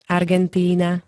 Argentína [-t-] -ny ž.
Zvukové nahrávky niektorých slov